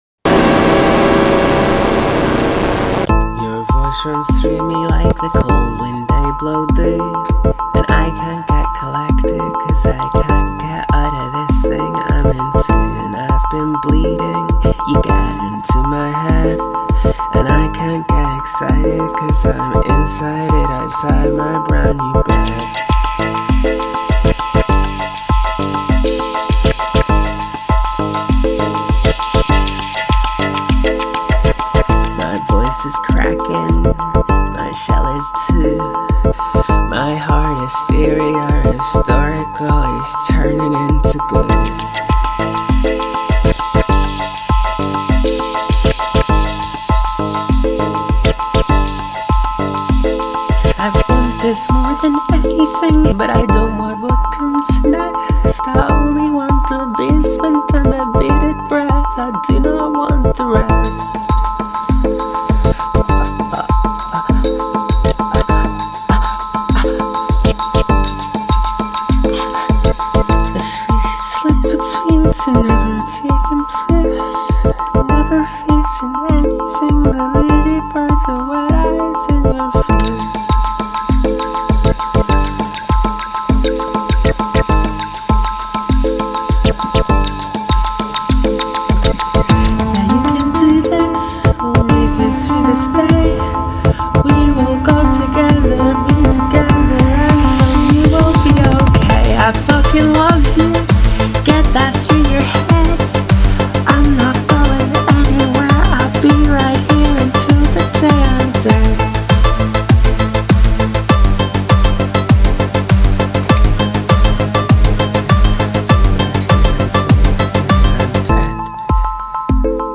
demo of whatever this song is called. maybe "goo"
Wrong BPM (is 100, wants to be 124), wrong octave sometimes, messed up the words twice, there are two parts that need flipped, there’s a bit that’s got singing that should be instrumental.